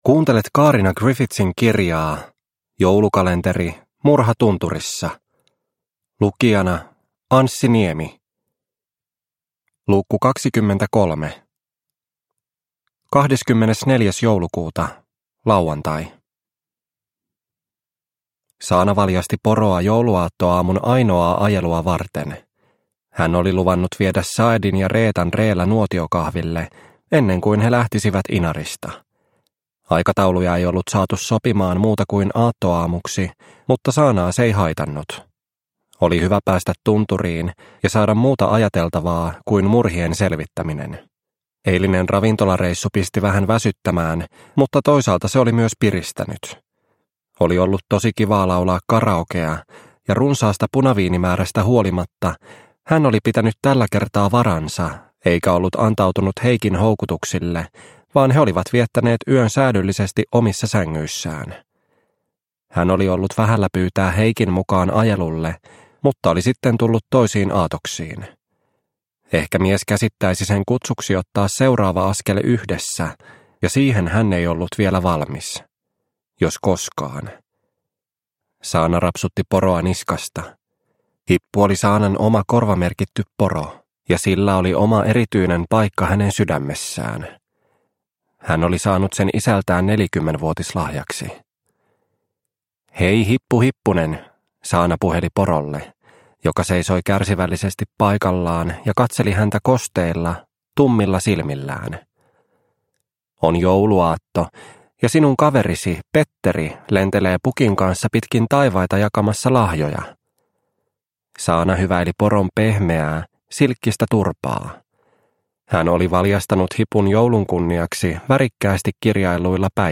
Murha tunturissa - Osa 23 – Ljudbok – Laddas ner